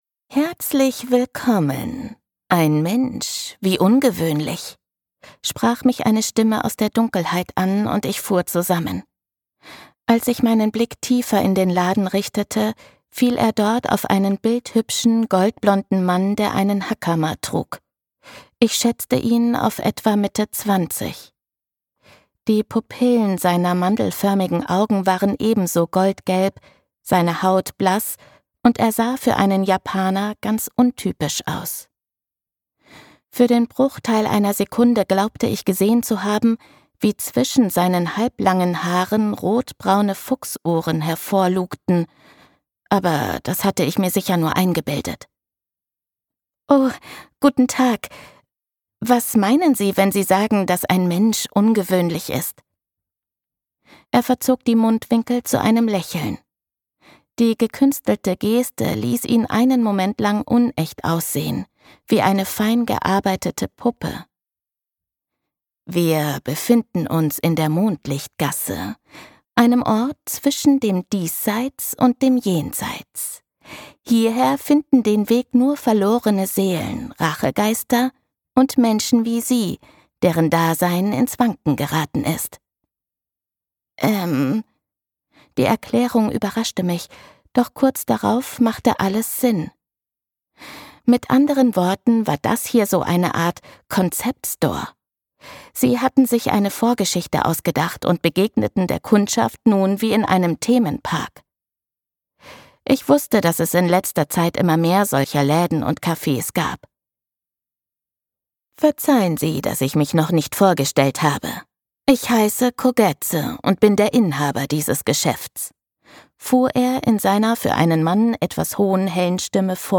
Das glücklich-machende Wohlfühl-Hörbuch über magische Süßigkeiten und den Zauber japanischer Märchen!
Gekürzt Autorisierte, d.h. von Autor:innen und / oder Verlagen freigegebene, bearbeitete Fassung.